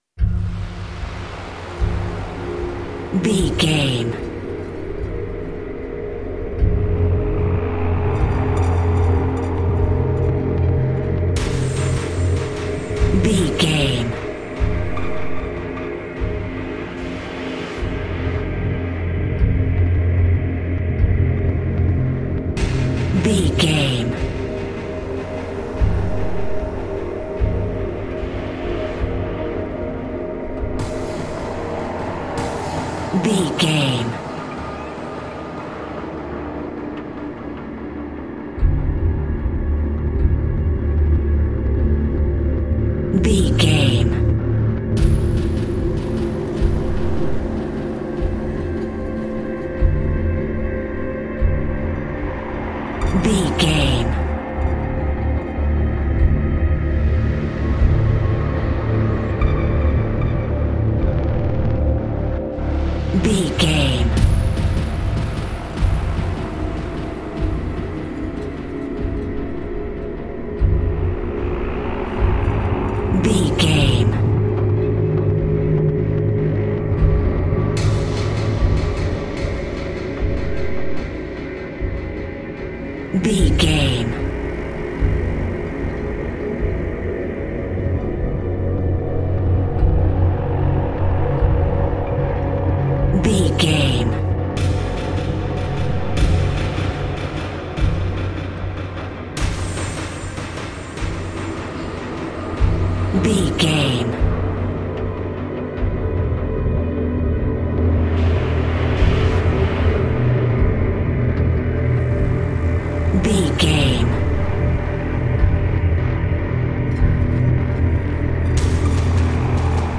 Thriller
Atonal
synthesiser
percussion
ominous
dark
suspense
haunting
tense
creepy